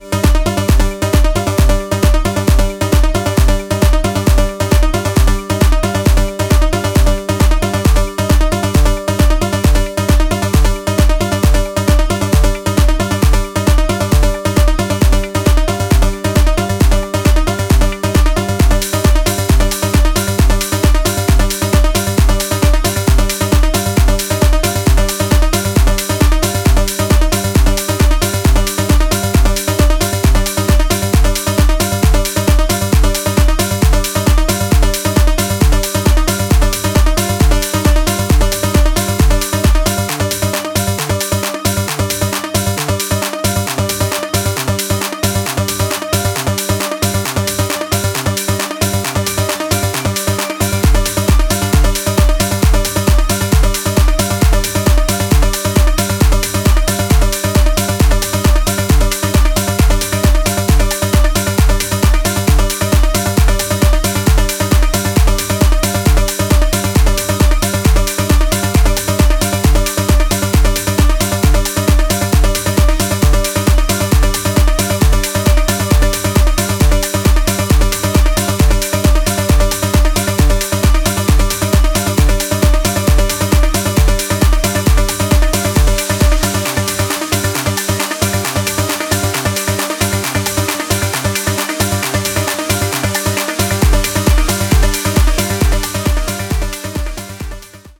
研ぎ澄まされた音響センス、パワフルなグルーヴ、流石の完成度です。